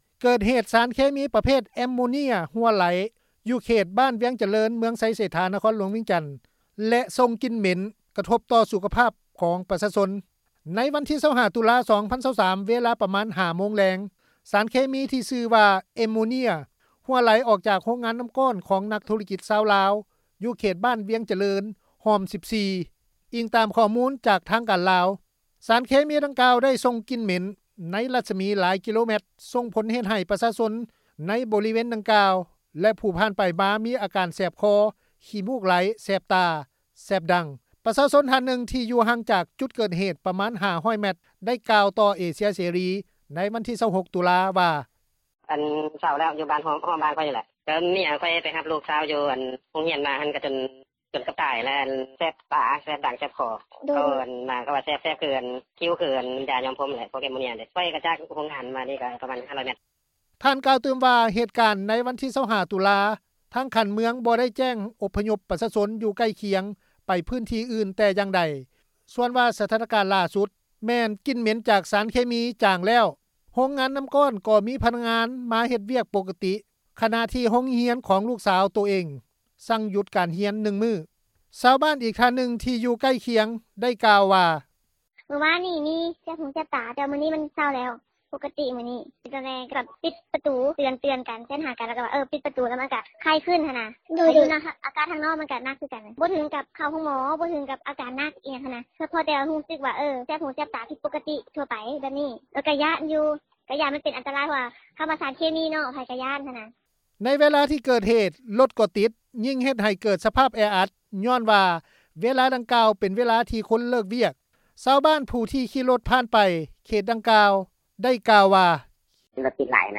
ຊາວບ້ານອີກທ່ານນຶ່ງ ທີ່ໃກ້ຄຽງ ໄດ້ກ່າວວ່າ:
ຊາວບ້ານຜູ້ທີ່ຂີ່ຣົຖຜ່ານ ໄປເຂດດັ່ງກ່າວ ໄດ້ກ່າວວ່າ: